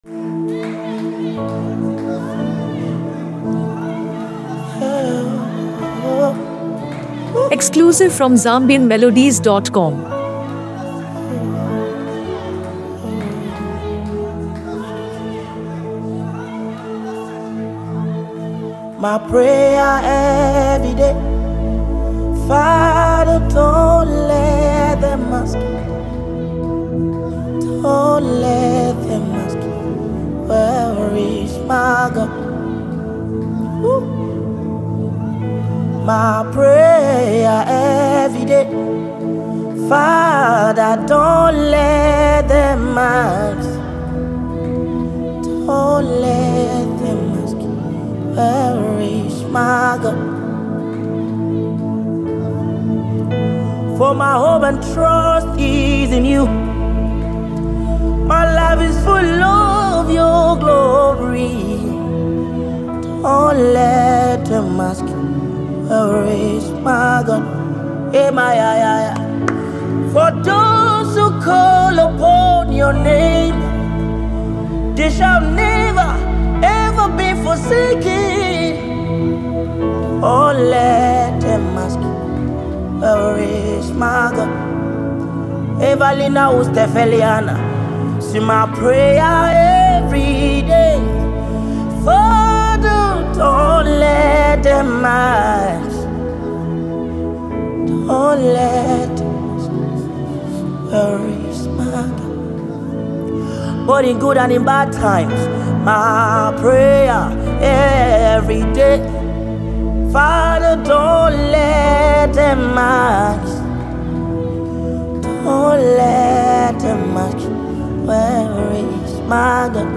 Searching for an uplifting song to inspire your daily life?